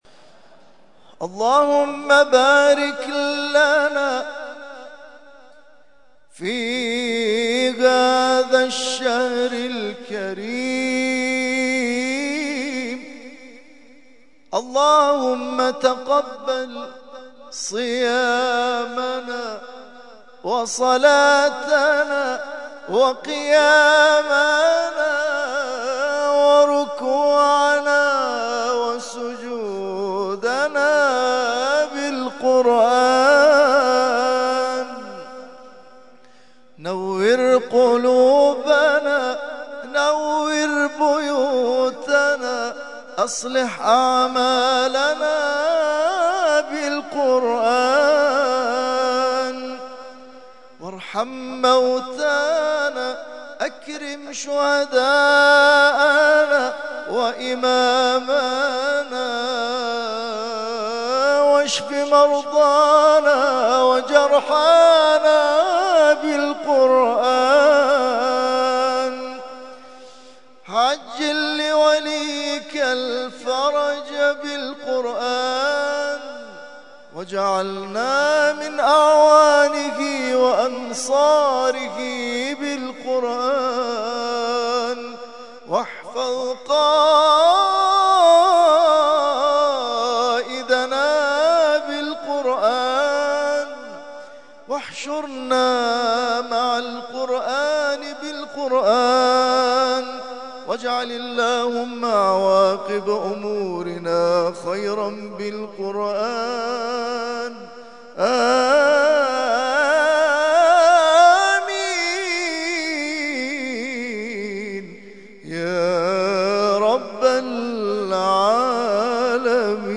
ترتیل خوانی جزء ۸ قرآن کریم در سال ۱۳۹۱